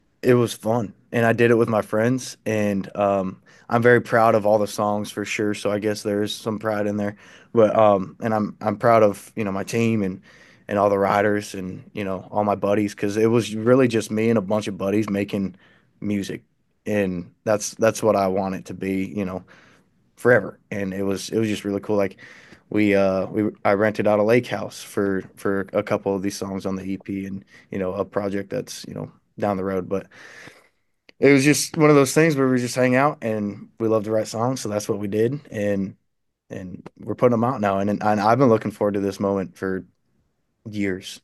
TUCKER WETMORE SPEAKS ON PROUDEST MOMENTS MAKING DEBUT EP (AUDIO)